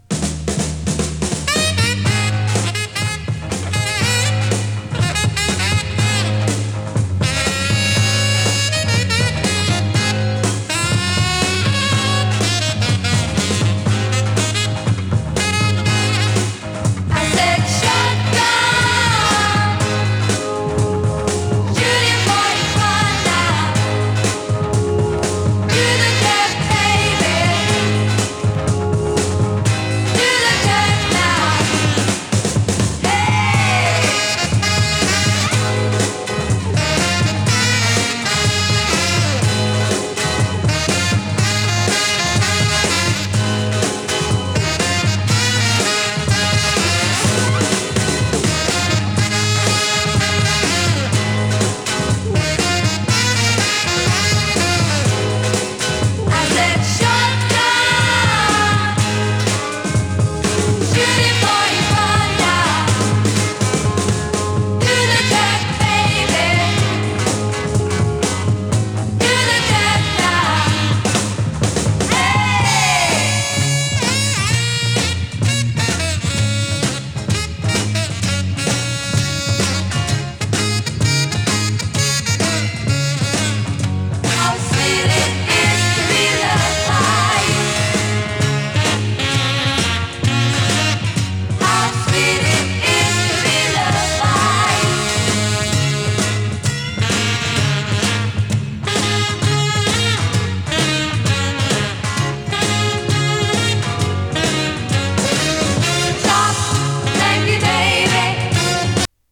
シャープな演奏に華やかな女性コーラスが花を添えたダンス・アルバムです！